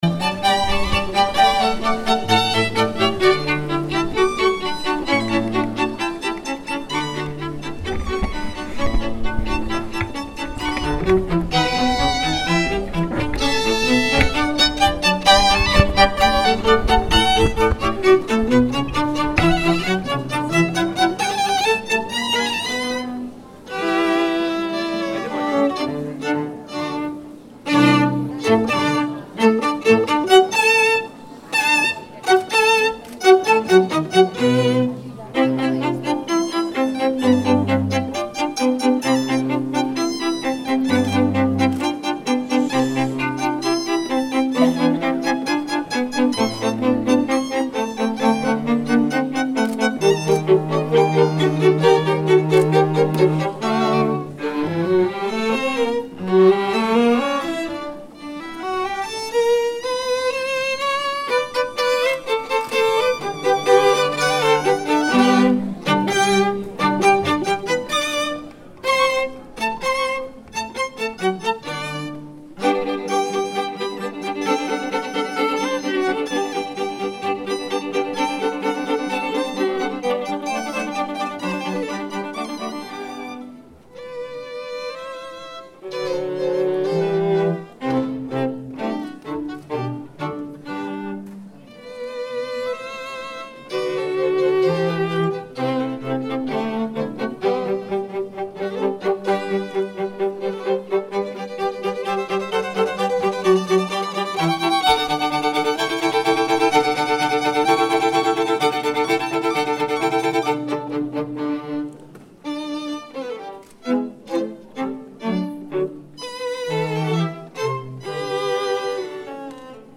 Duminica Mironosițelor – Sărbătoare la Săvinești